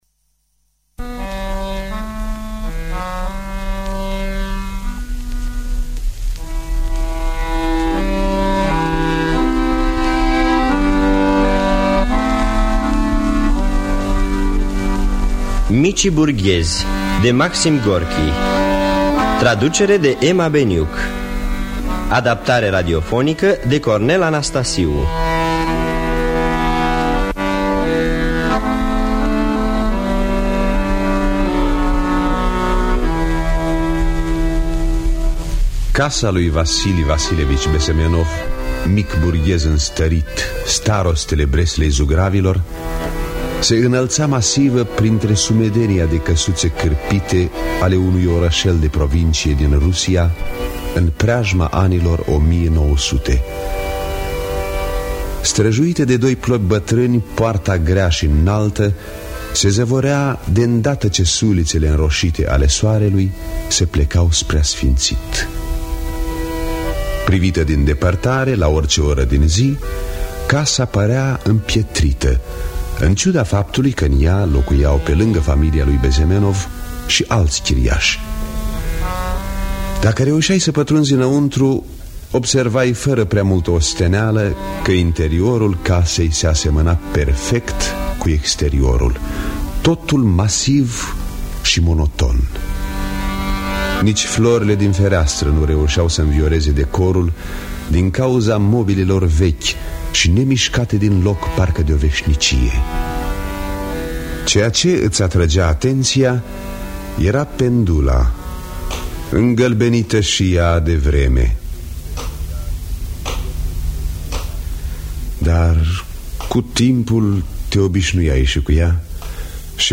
În distribuţie: George Calboreanu, Marietta Sadova, Clody Berthola, Mircea Albulescu, Liliana Tomescu, Toma Dimitriu.